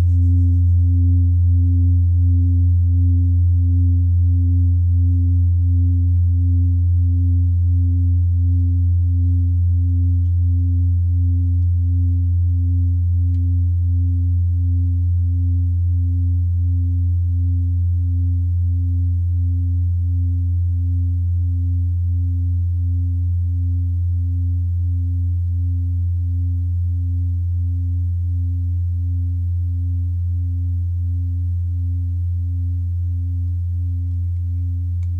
Klangschale TIBET Nr.44
Klangschale-Durchmesser: 30,4cm
Sie ist neu und ist gezielt nach altem 7-Metalle-Rezept in Handarbeit gezogen und gehämmert worden..
(Ermittelt mit dem Filzklöppel oder Gummikernschlegel)
In unserer Tonleiter liegt dieser Ton nahe beim "Fis".
klangschale-tibet-44.wav